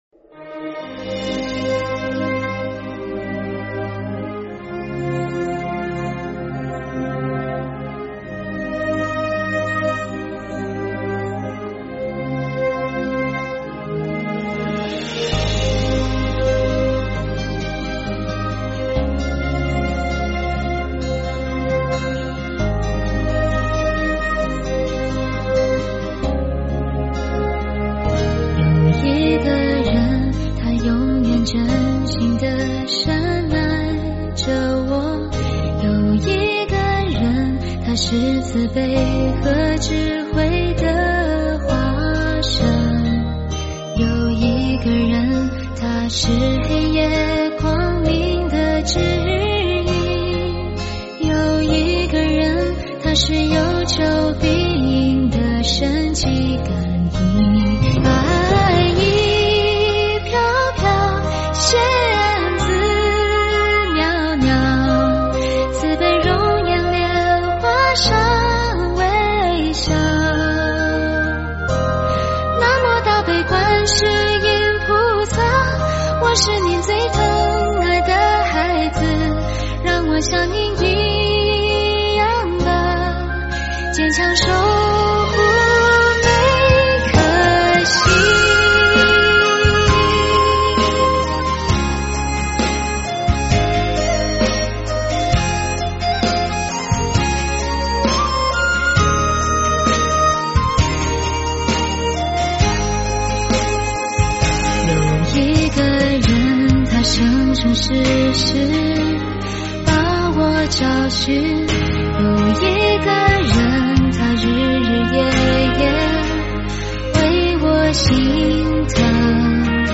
佛音 凡歌 佛教音乐 返回列表 上一篇： 聆听(大悲咒